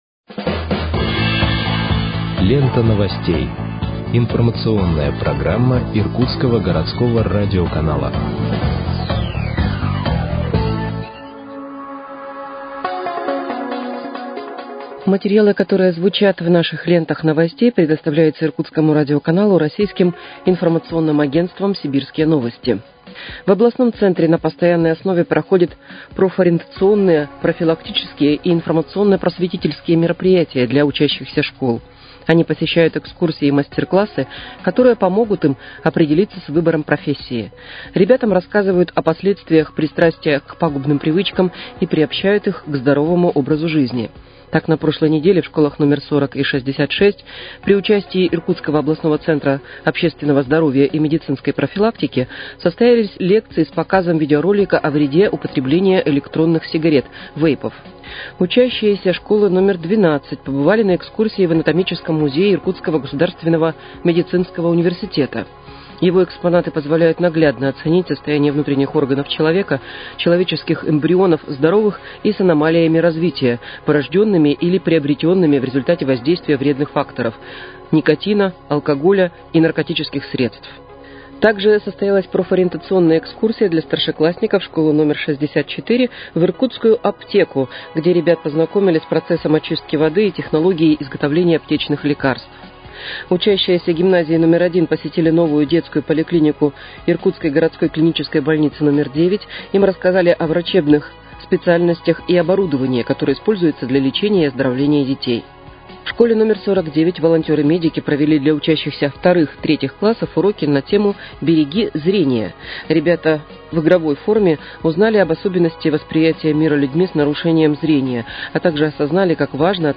Выпуск новостей в подкастах газеты «Иркутск» от 26.03.2025 № 1